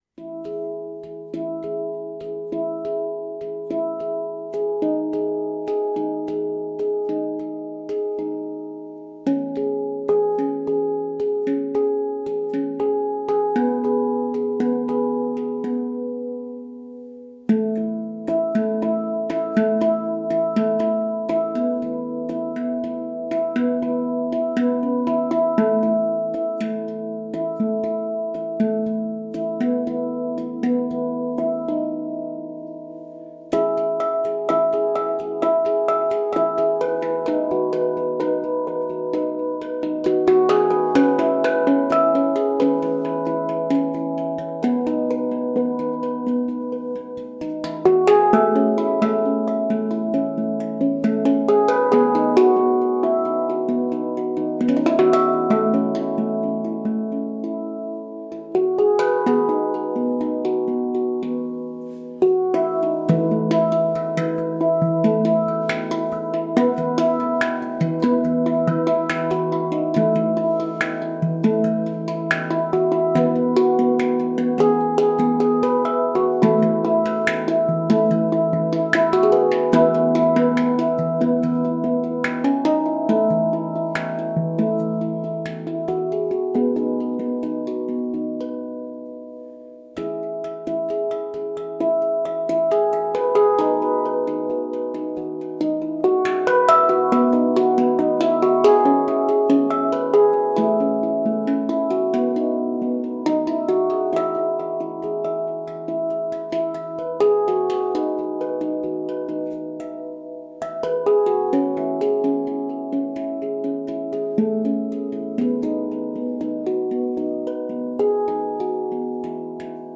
E A B C# Eb E F# G# B E